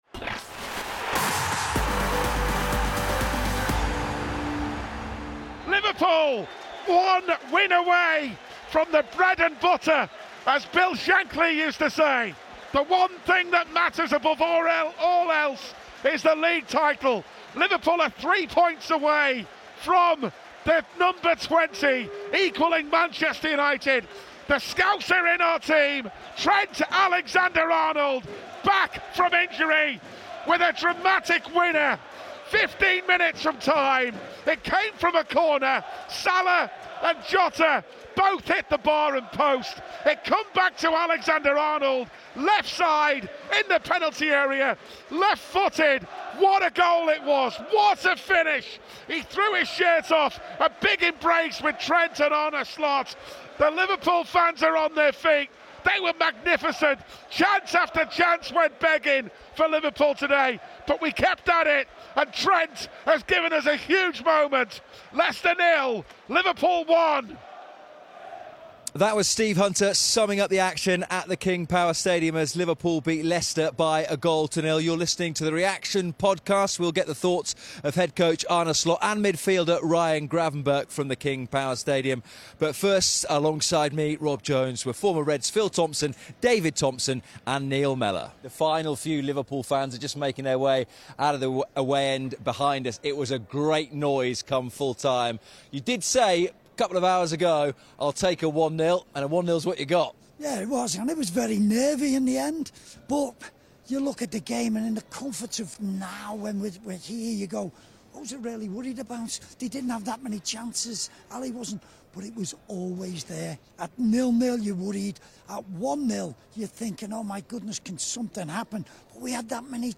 Join us for post-match reaction from The King Power Stadium as Liverpool's 1-0 win at Leicester means one more win will seal a 20th league title.